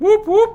woop-woop.mp3